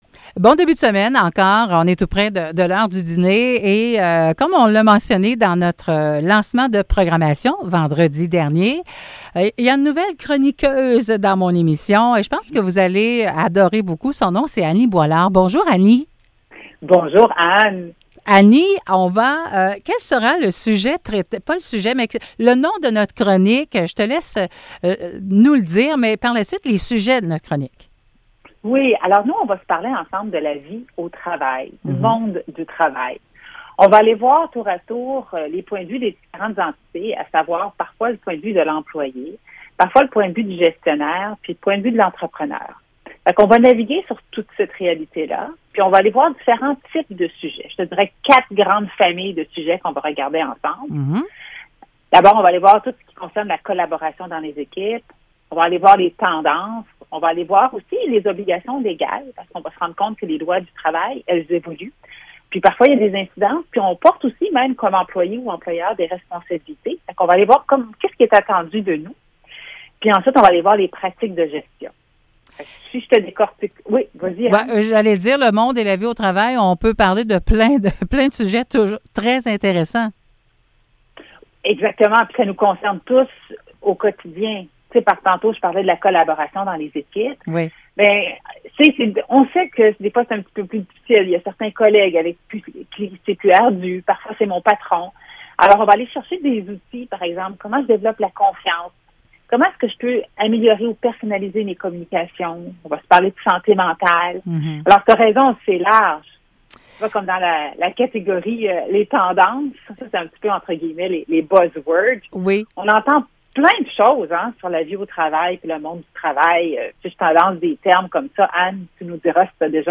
Chroniques